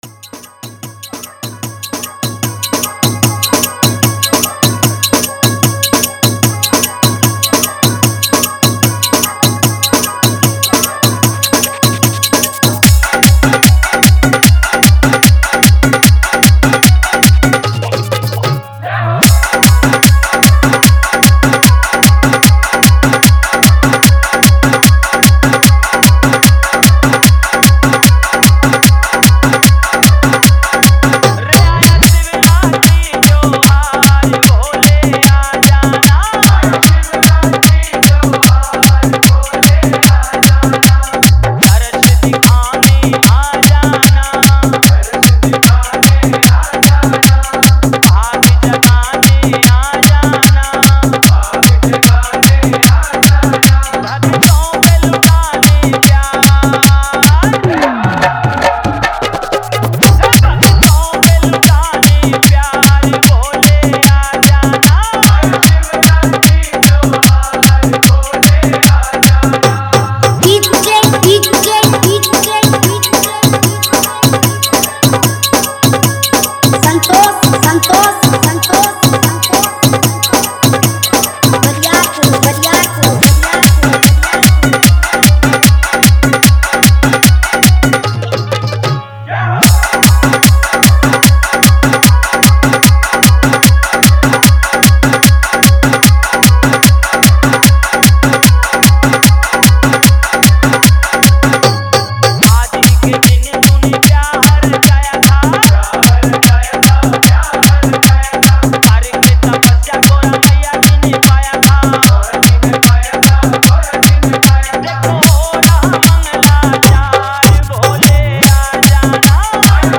Bhakti Dj Remix Songs 2025